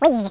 wizz.wav